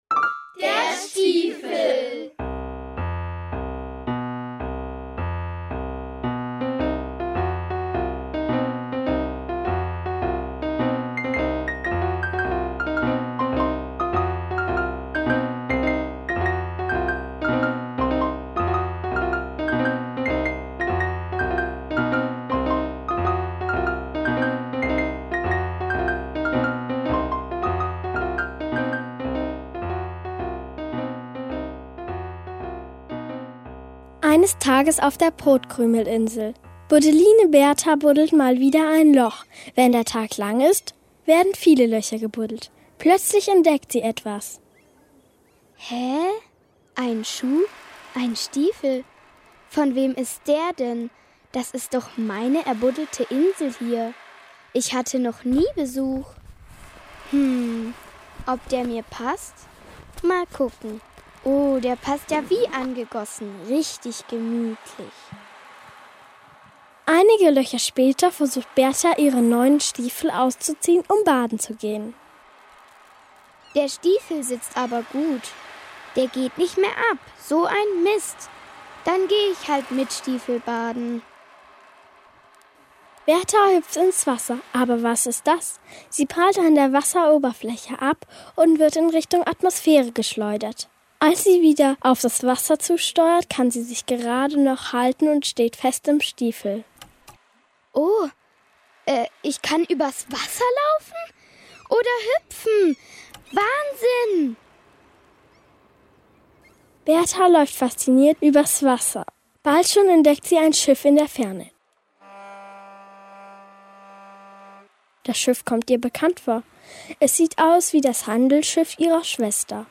In den Fachingsferien 2026 enstand in den Radioräumen ein neues Kurzhörspiel.
Im Rahmen eines Kinderferienkurses wurde die Story entwickelt, diese dann am Mikrofon eingesprochen und kreativ die Geräusche dazu aufgenommen. Zum Schluss wurde dann noch die Musik komponiert und das Titelbild gestaltet.